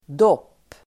Uttal: [dåp:]